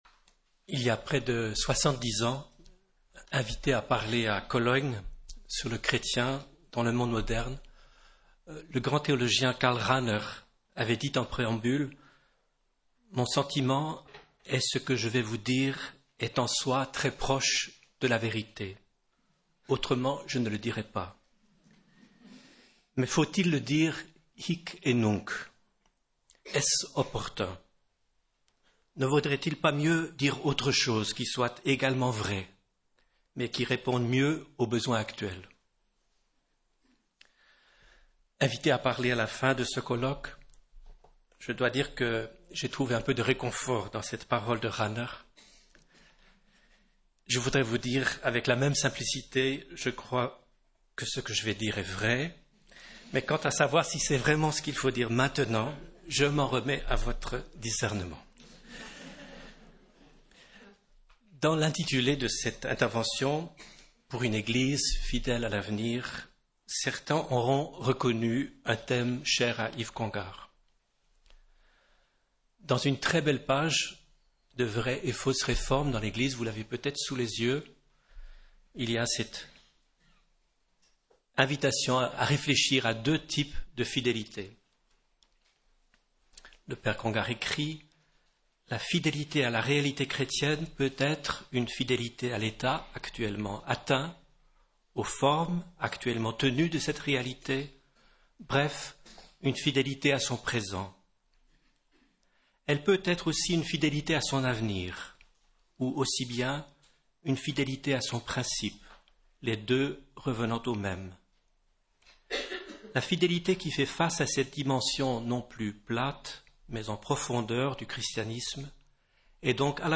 Colloque de rentrée du Centre Sèvres